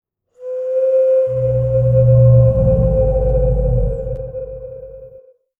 long-howl-whale-and-monster.wav